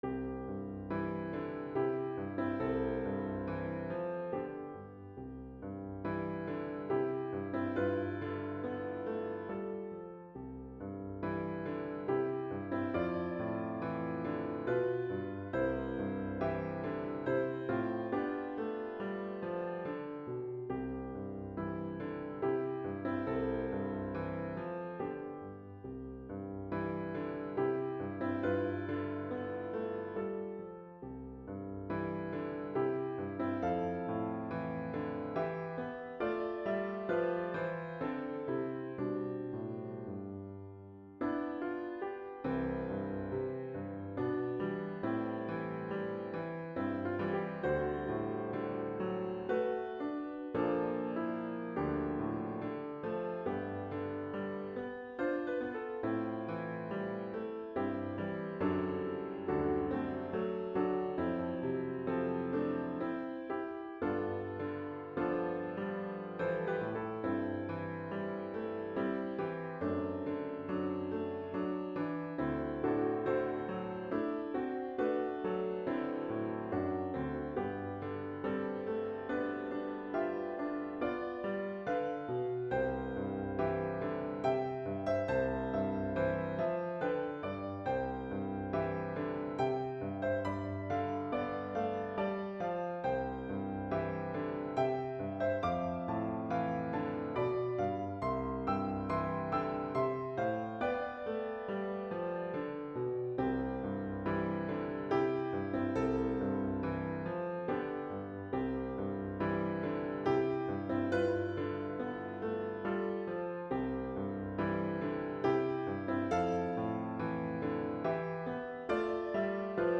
About the music: It is written in the key of C major. It is in a simple two part AB form, that is repeated two times, with variations on the music each time it is repeated.